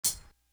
Closed Hats
Spellman Hat.wav